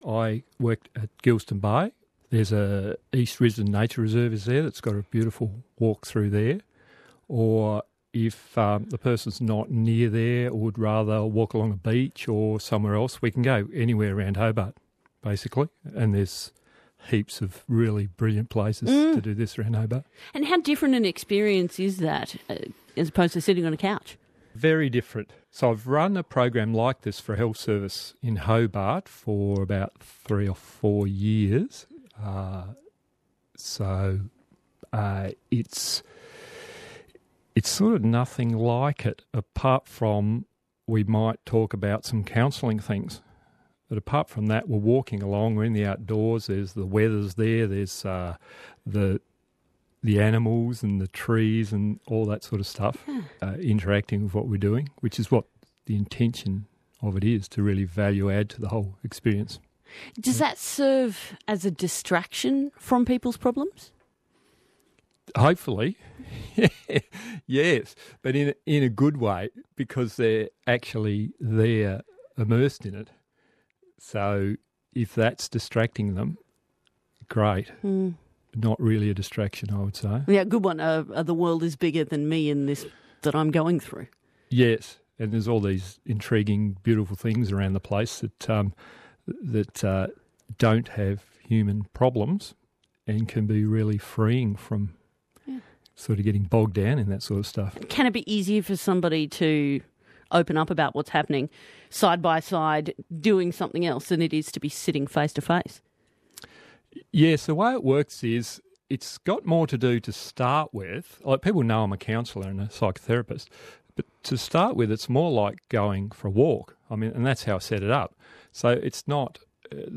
I was interviewed